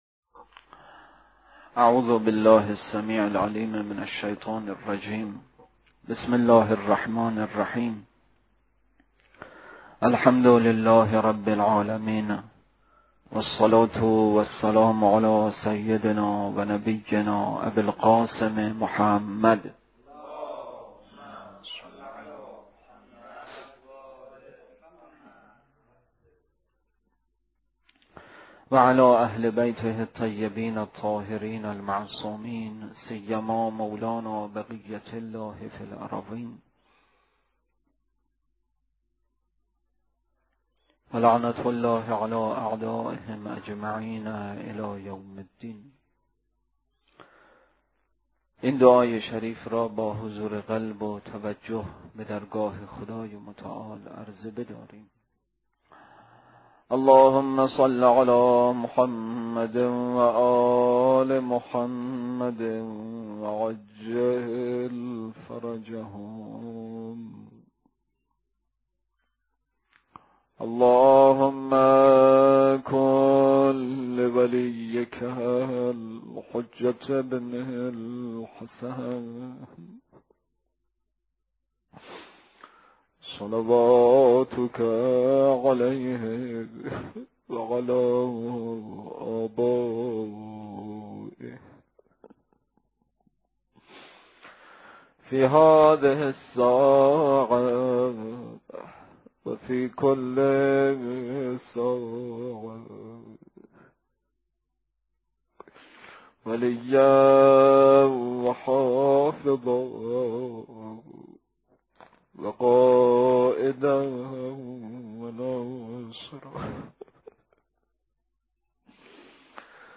روضه: مواجهه‌ی کاروان سیدالشهدا(غ) با لشکر دشمن، ورود به کربلا مرورگر شما فایل صوتی را پشتیبانی نمی کند.